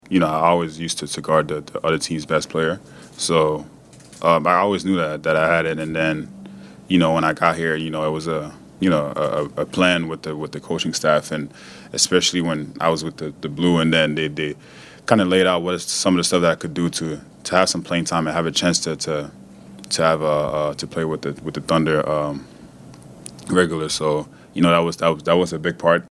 At Oklahoma City’s media availability earlier this week,